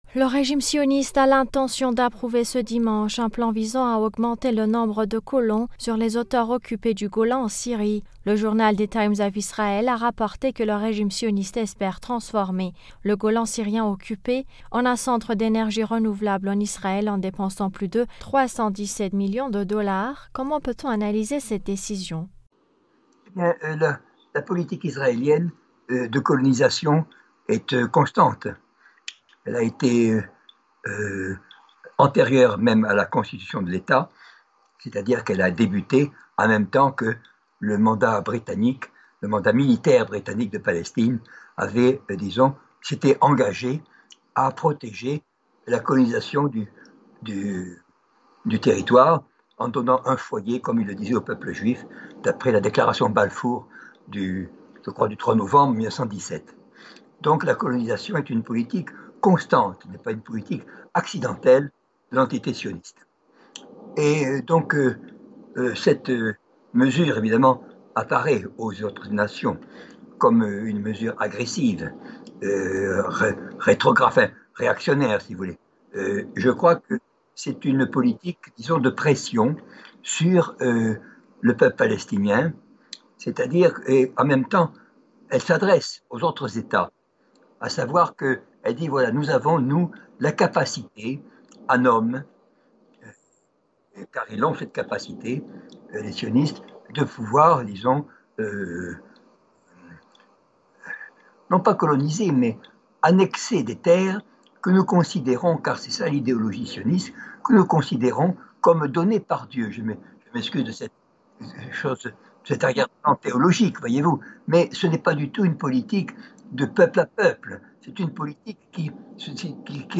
politologue, s’exprime sur le sujet.